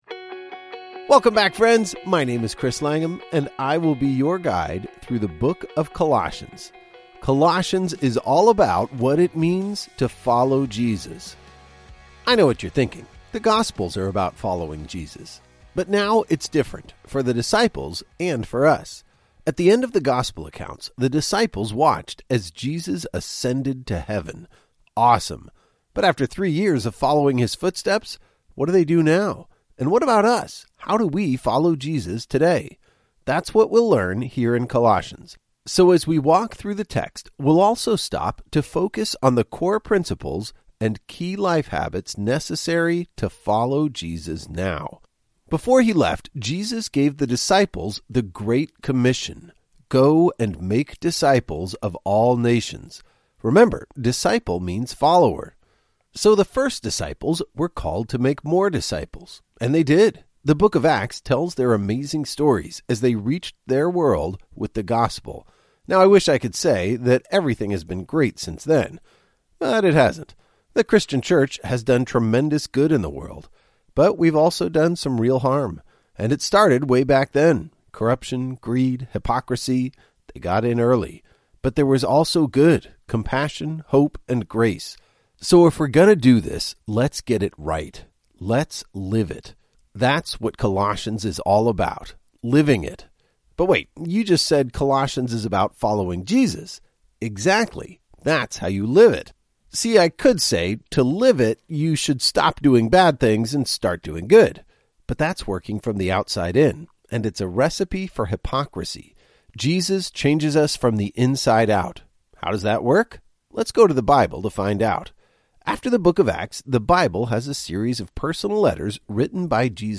Each journey is an epic adventure through several Bible books, as your favorite pastors explain each chapter in a friendly and compelling audio guide. Journey #1 is the perfect start with 24 days through Mark and Colossians.